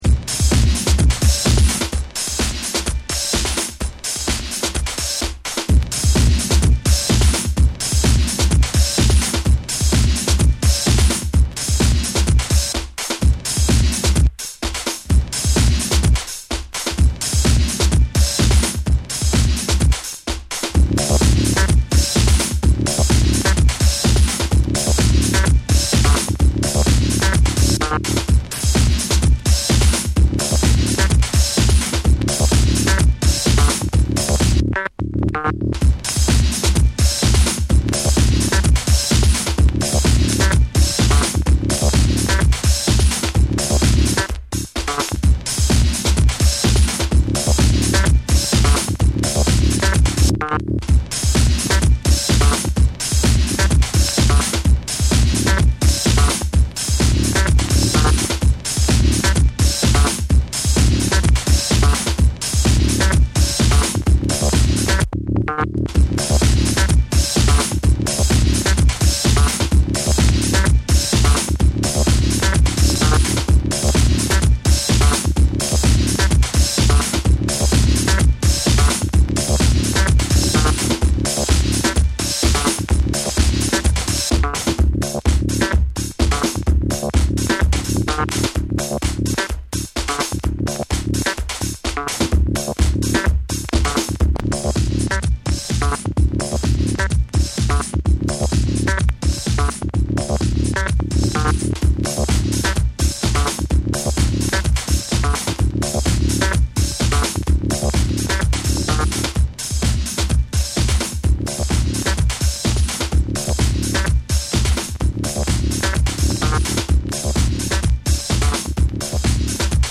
TECHNO & HOUSE / DETROIT